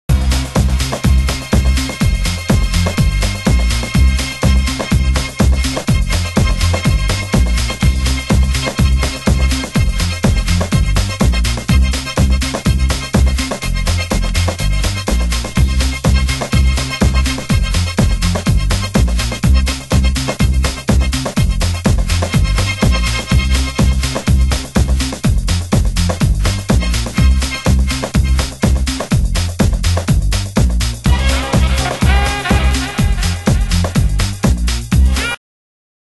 HOUSE MUSIC
ワイルドなネタ使い、フィルター具合もライブフィーリング溢れるトラック！